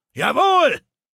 Datei:Maleold01 ms06 goodbye 000bc2e7.ogg